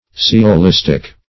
Sciolistic \Sci`o*lis"tic\, a.